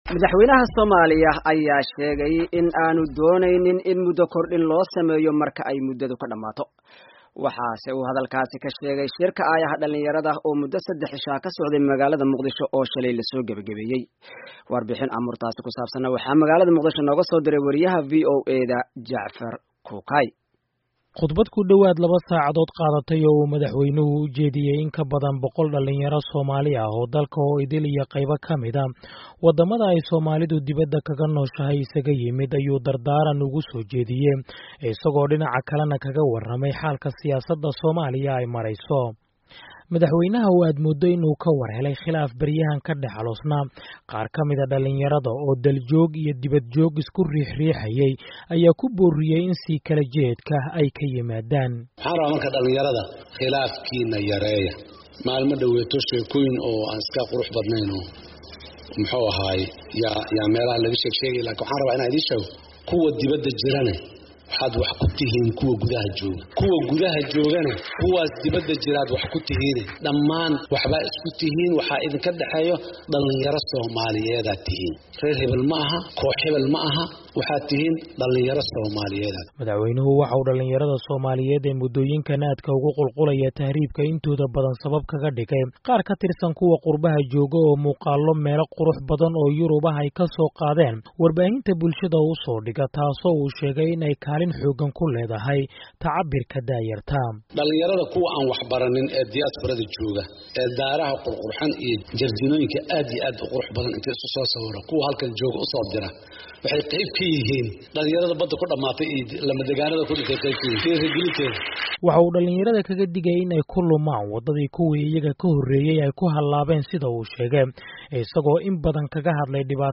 Khudbadda Madaxweyne Xasan Sheekh